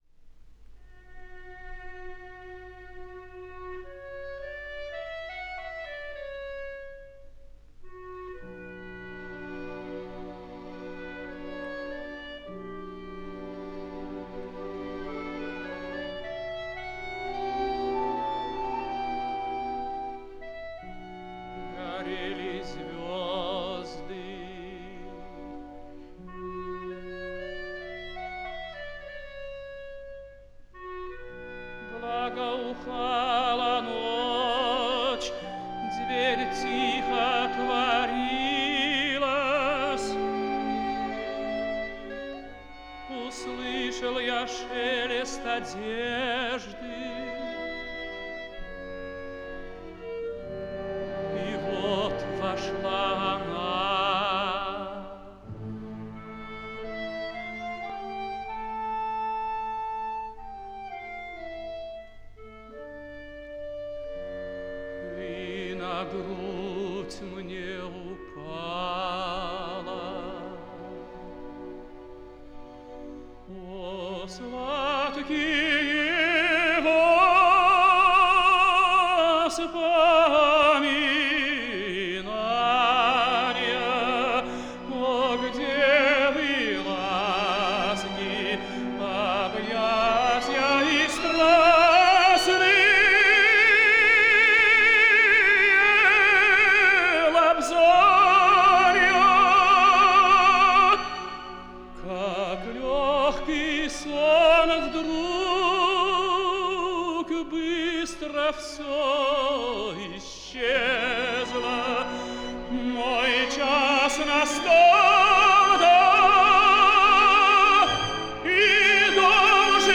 Сергей Лемешев - Ария Каварадосси из 3-го д. (Дж.Пуччини. Тоска) (1954)